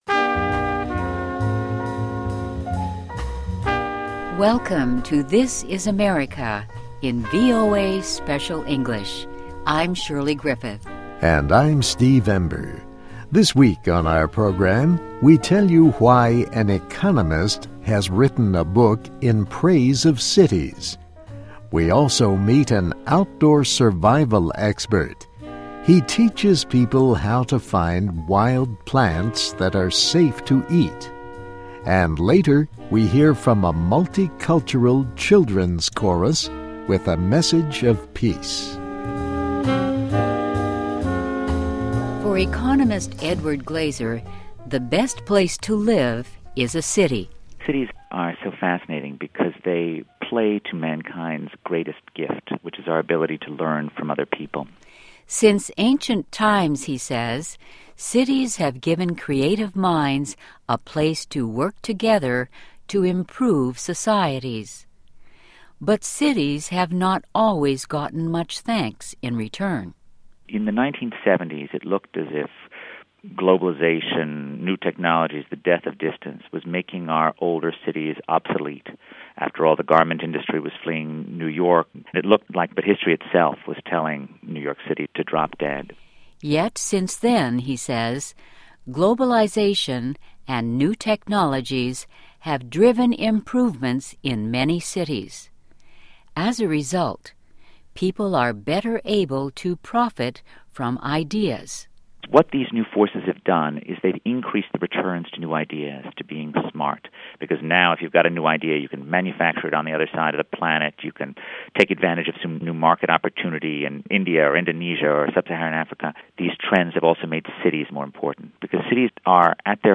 se-tia-city living-wild edibles-multicultural chorus.Mp3